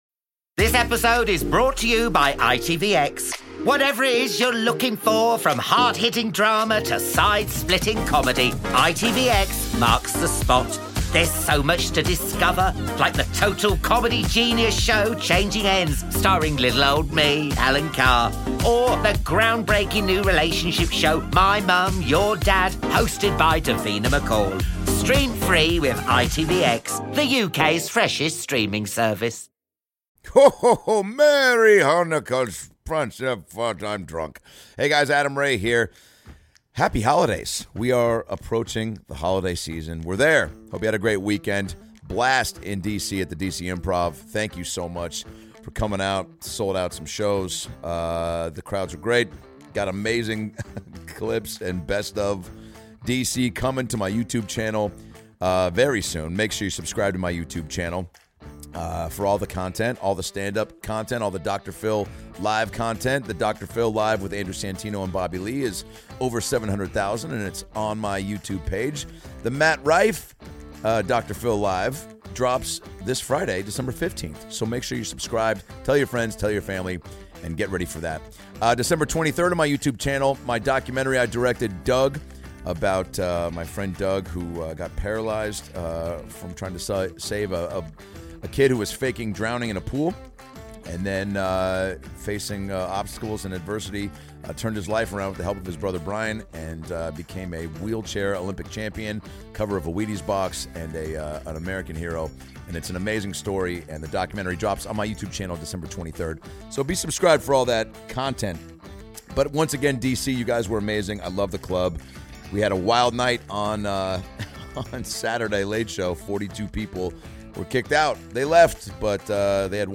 Adam Ray as Dr. Phil Andrew Santino as himself Bobby Lee as himself